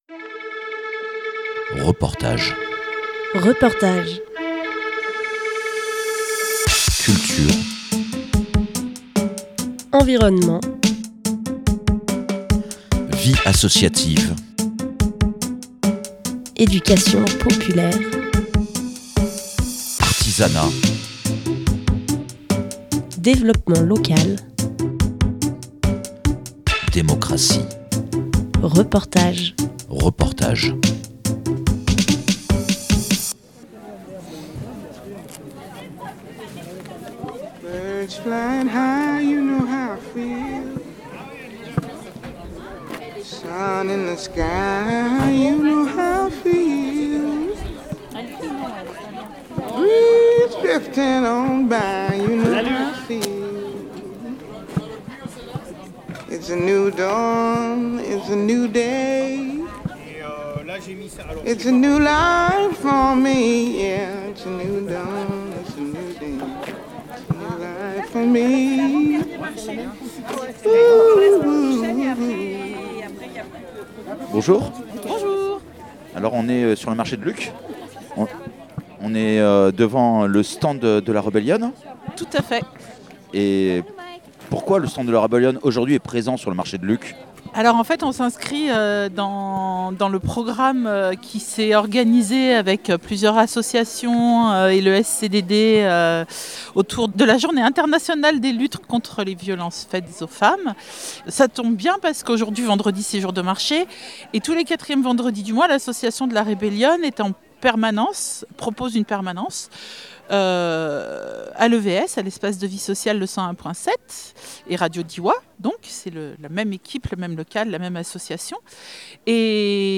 Emission - Le 101.7, Espace de Vie Sociale , Reportages La Rebelionne sur le marché de Luc en Diois pour la Journée internationale de lutte contre les violences faites aux femmes.
lieu : Marché de Luc en Diois